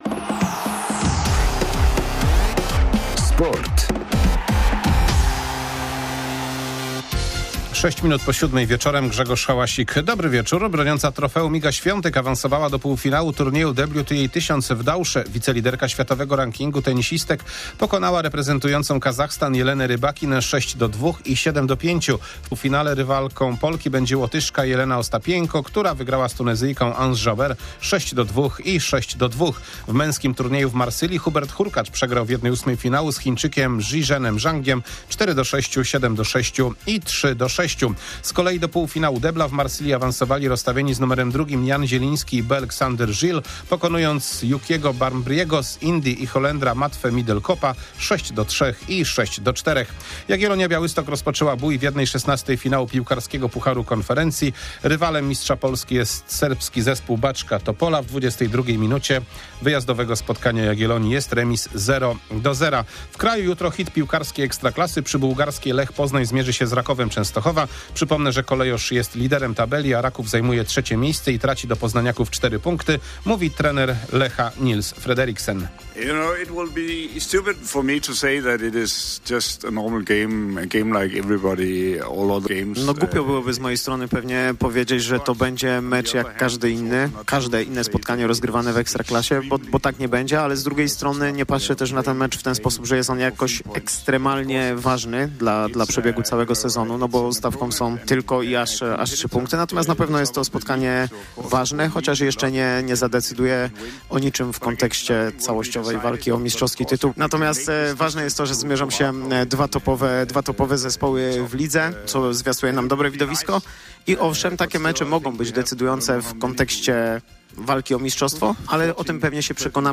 13.02.2025 SERWIS SPORTOWY GODZ. 19:05